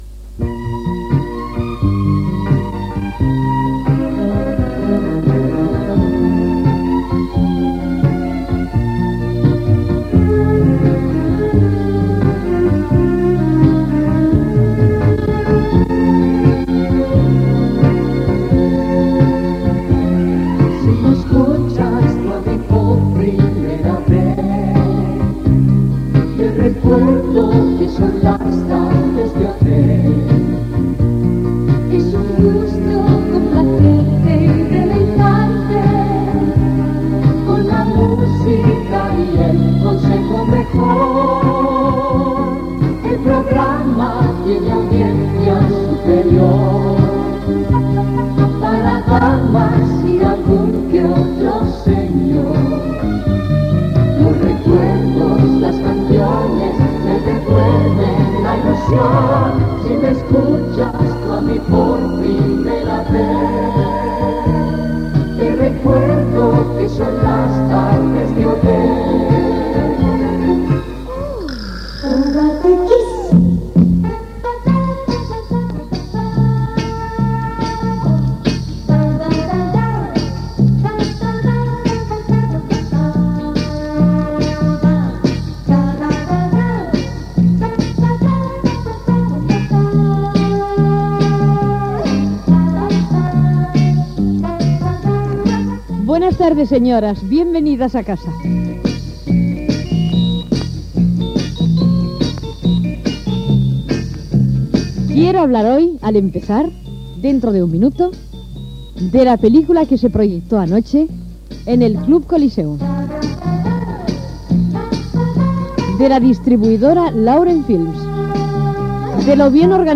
Cançó "jingle del programa", sintonia de l'espai, benvinguda al programa, esment a una pel·lícula estrenada al cinema Club Coliseum de Barcelona i trucada telefònica.
Entreteniment